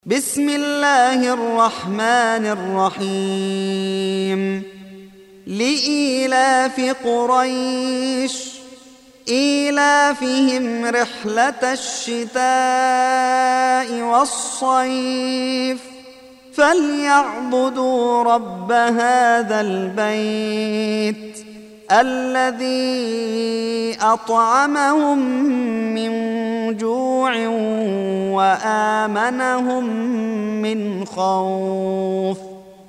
Surah Sequence تتابع السورة Download Surah حمّل السورة Reciting Murattalah Audio for 106. Surah Quraish سورة قريش N.B *Surah Includes Al-Basmalah Reciters Sequents تتابع التلاوات Reciters Repeats تكرار التلاوات